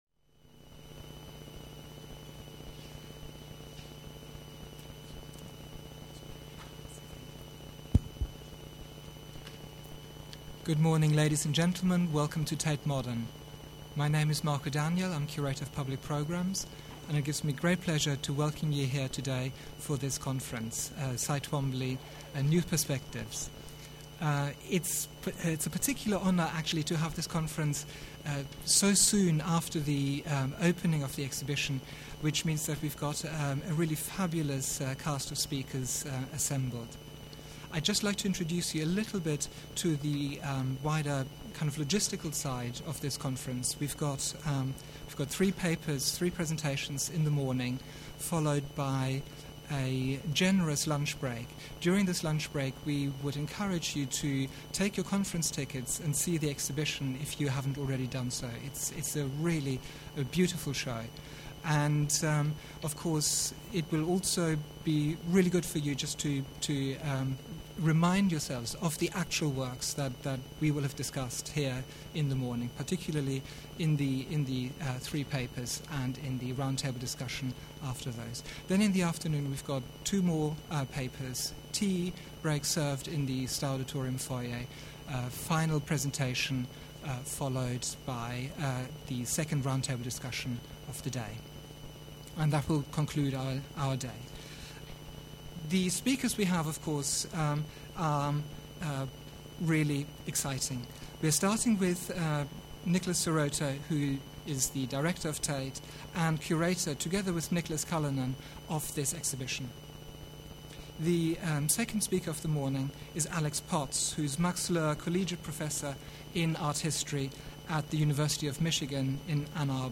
Coinciding with the major Cy Twombly exhibition at Tate Modern, audio coverage of this past symposium presenting new research on the artist and his works